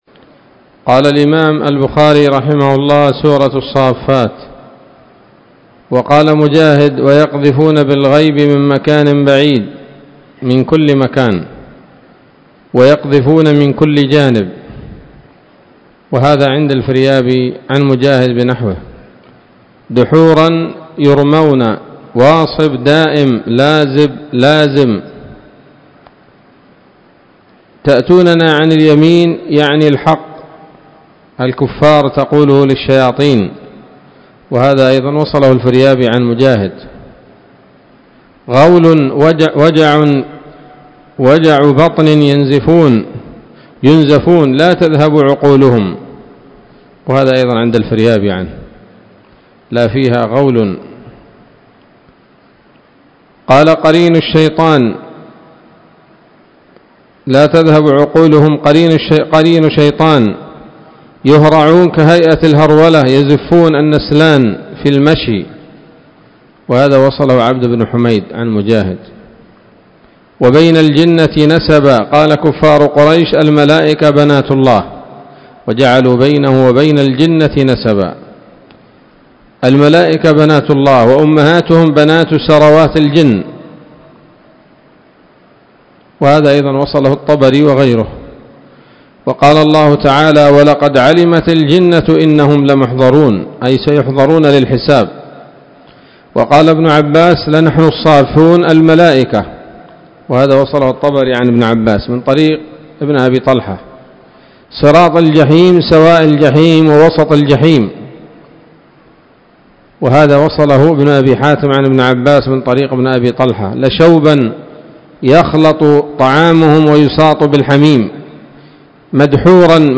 الدرس الثالث عشر بعد المائتين من كتاب التفسير من صحيح الإمام البخاري